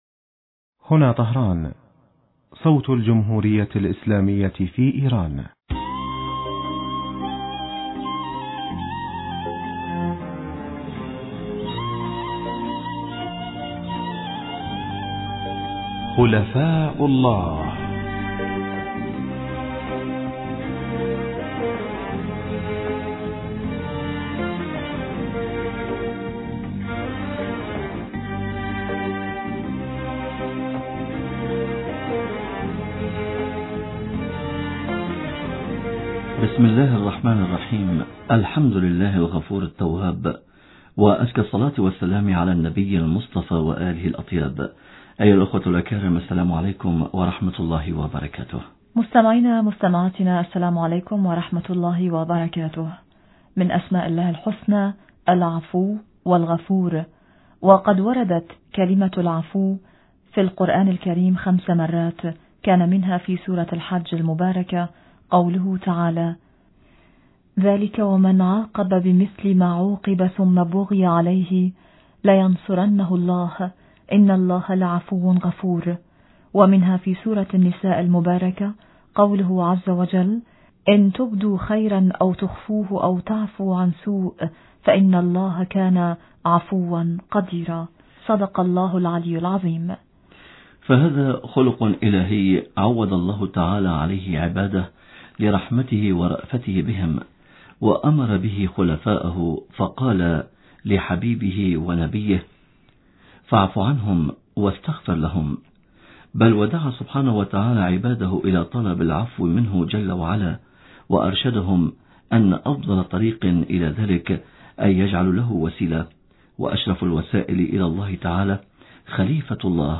عرضنا هذا السؤال في اتصال هاتفي